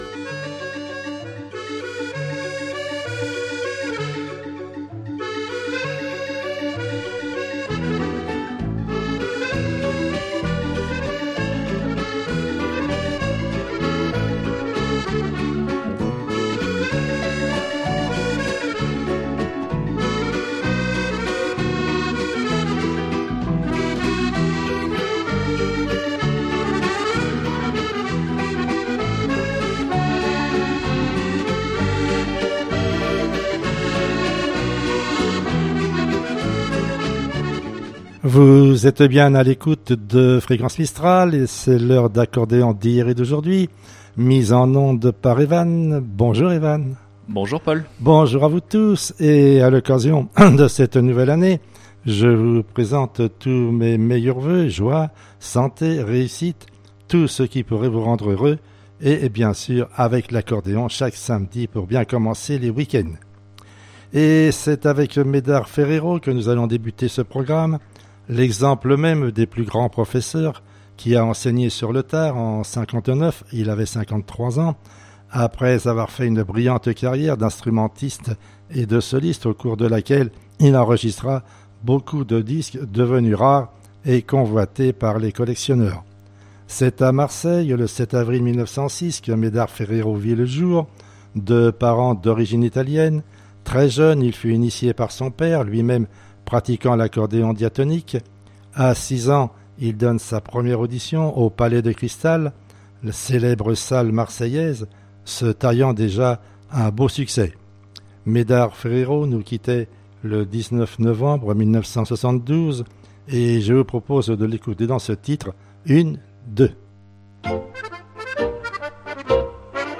Accordéon d'hier et d'aujourd'hui-2017-01-07.mp3 (29.27 Mo)